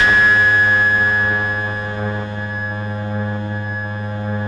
RESMET G#2-L.wav